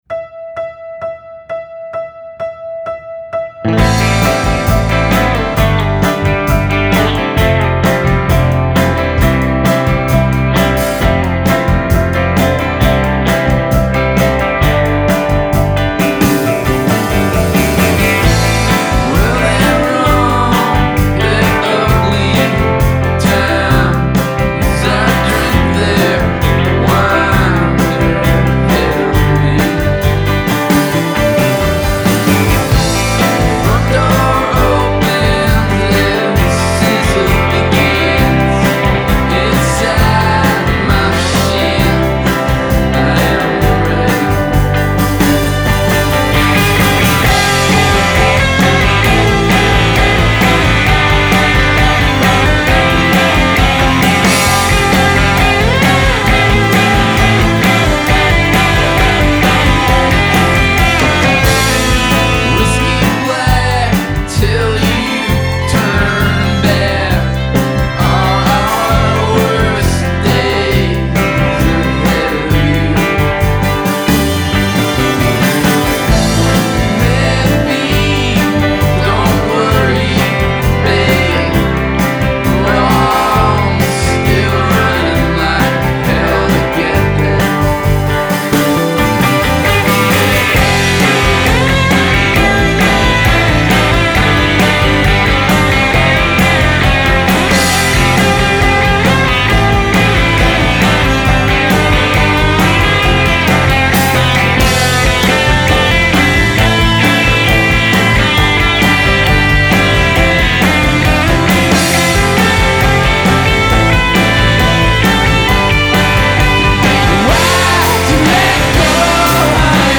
superbly pleasant alt-country vibe